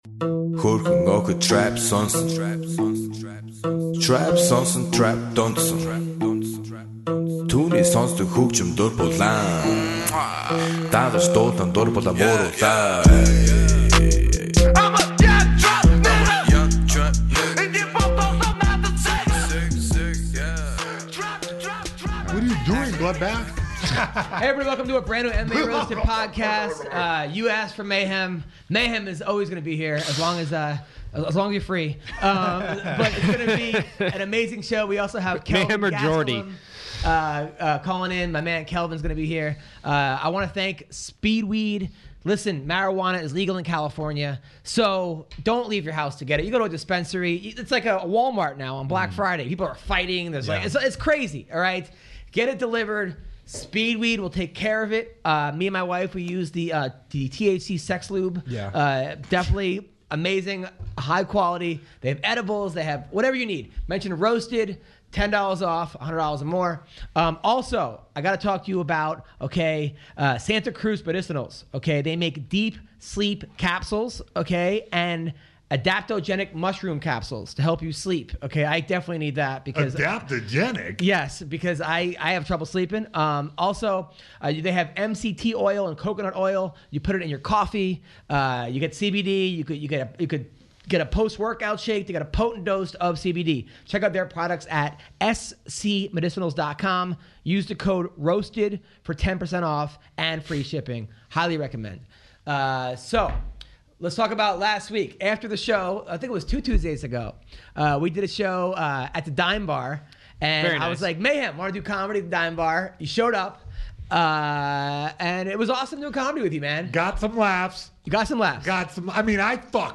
UFC fighter and friend of the show Kelvin Gastelum also calls in to talk about upcoming fights, and when he will make his return to the octagon.